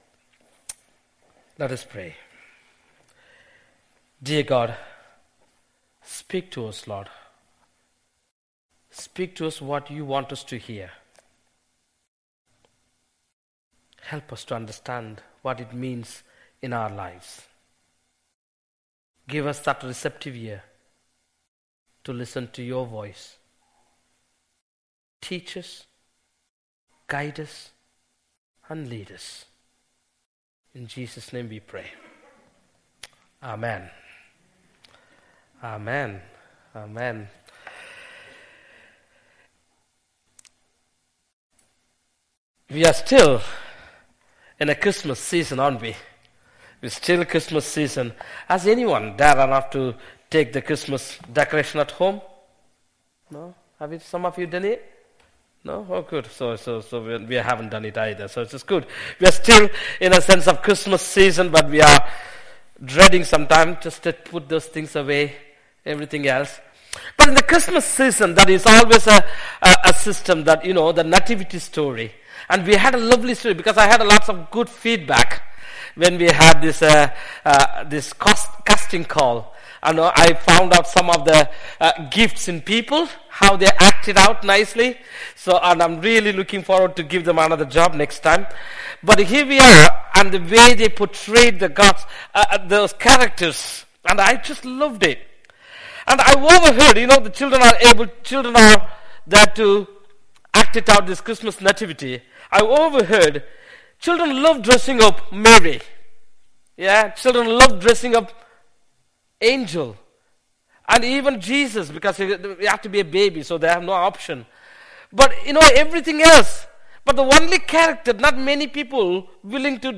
An audio file of the sermon is also available.